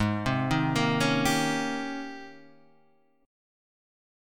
G# Major 11th